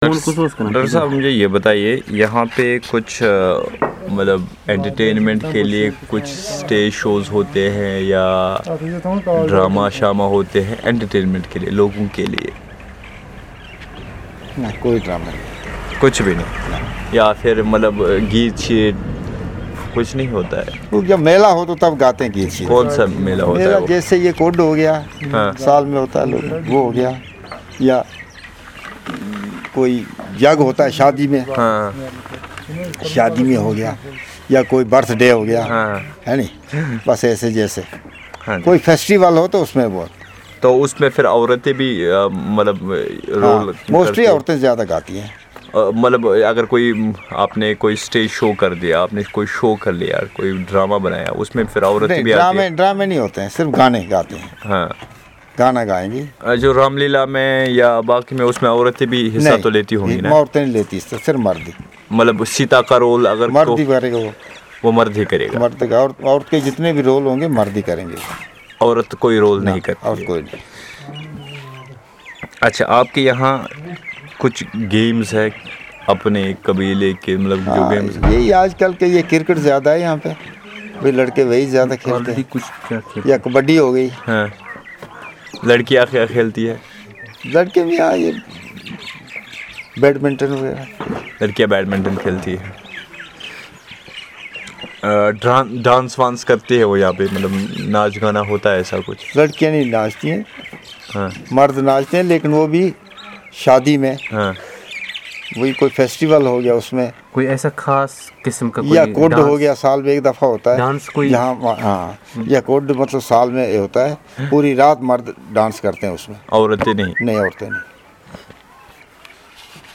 Conversation on the means of entertainment in the community